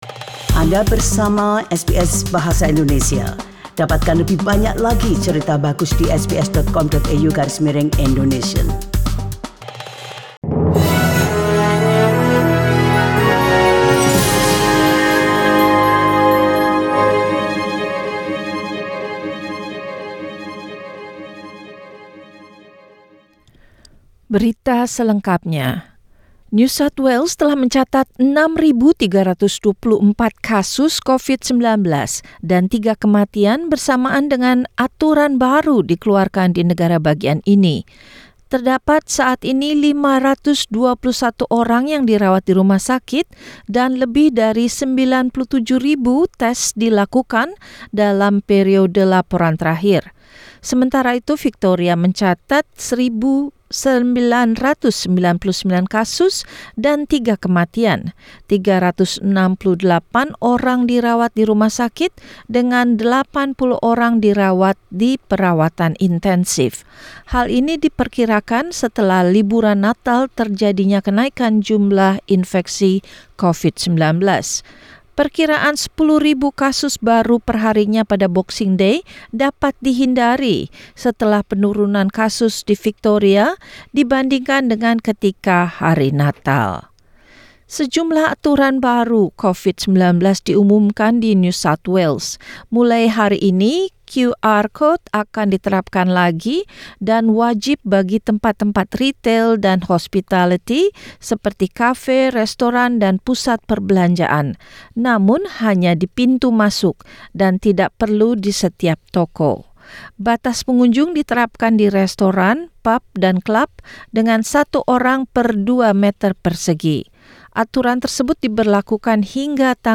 SBS Radio News in Indonesian - Monday, 27 December 2021
Warta Berita Radio SBS dalam Bahasa Indonesia Source: SBS